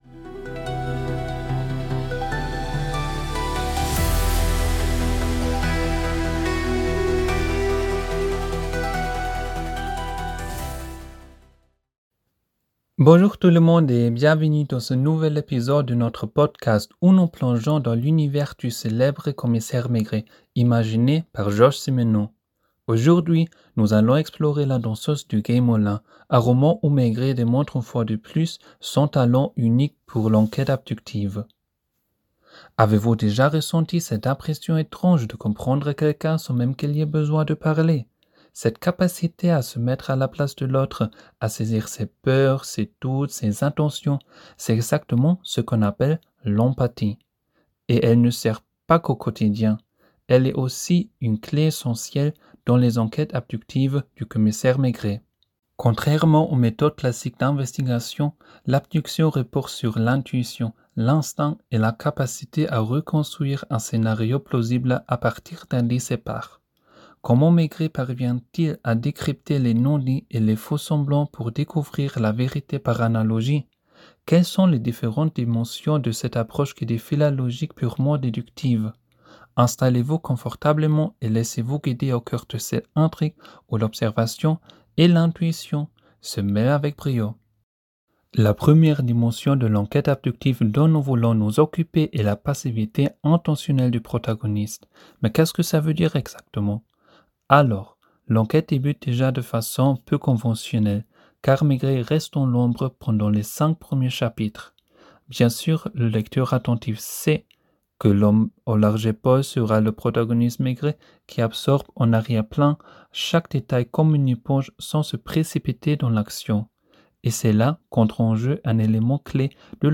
Des experts expliquent ce qui rend le personnage de Maigret si unique et pourquoi ses histoires continuent de fasciner aujourd’hui.